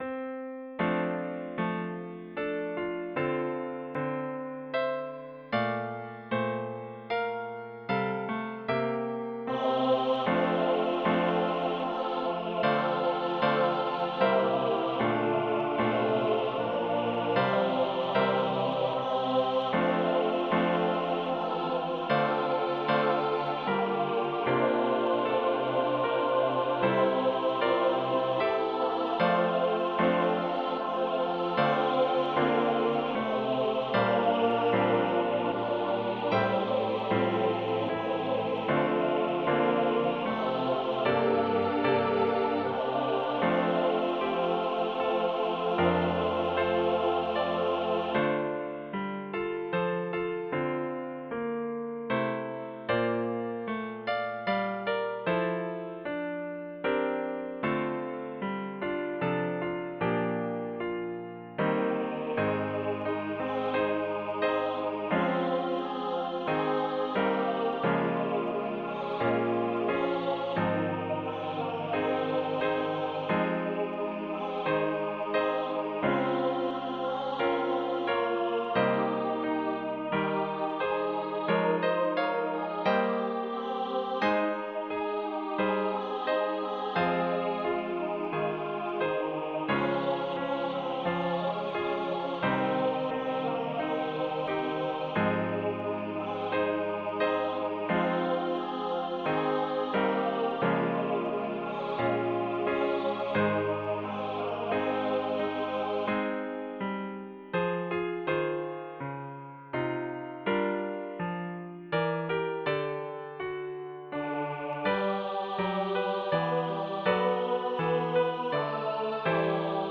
SATB
Le deuxième couplet est un duo polyphonique où les ténors chantent la mélodie principale tandis que toutes les voix féminines chantent la contre-mélodie. Tous les chanteurs interprètent le troisième couplet à l'unisson.
En plus de la partition complète, j'inclus également une partition des parties vocales de 2 pages et un fichier sonore généré par ordinateur.